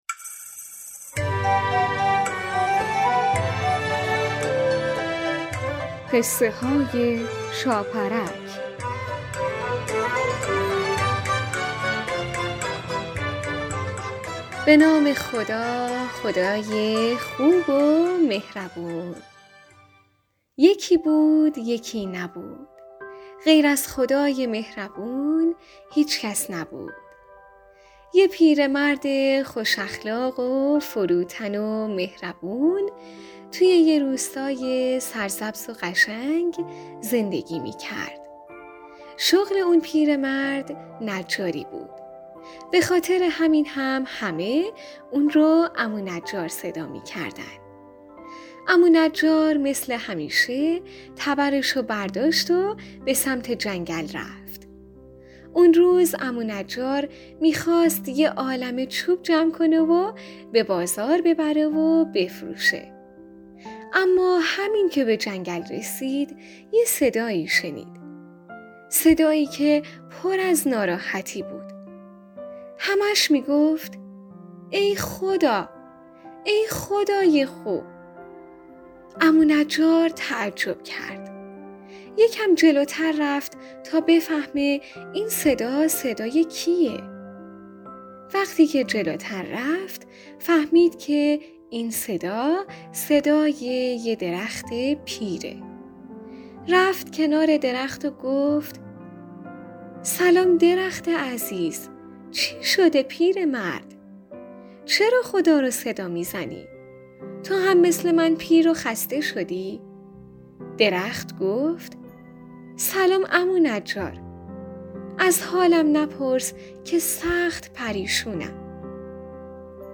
قسمت صد و هجدهم برنامه رادیویی قصه های شاپرک با نام آرزوی درخت یک داستان کودکانه مذهبی با موضوعیت نماز است که ...